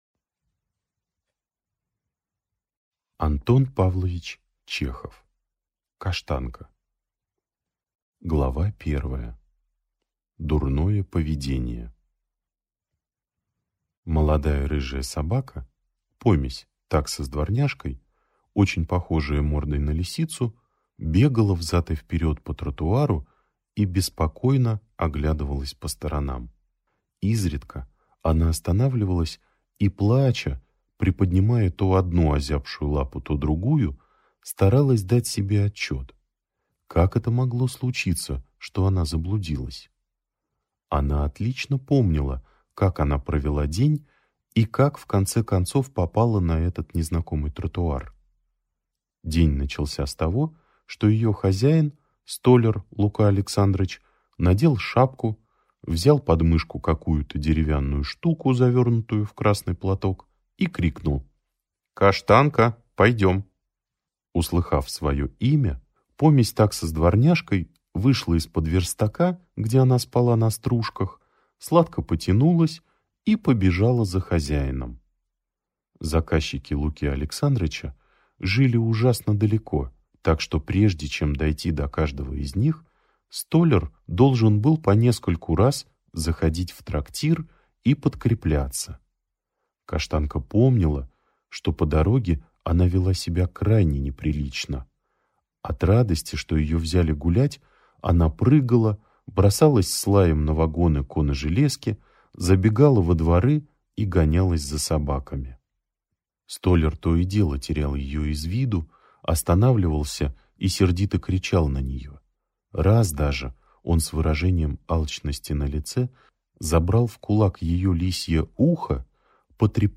Аудиокнига Каштанка | Библиотека аудиокниг